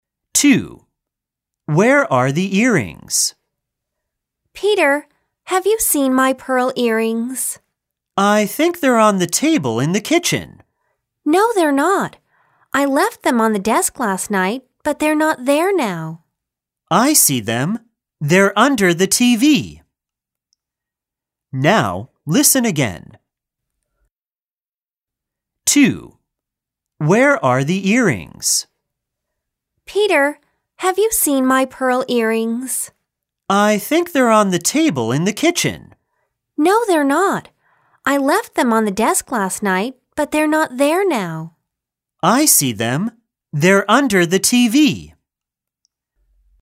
A2 聽力測驗錄音檔 (可直接播放或下載)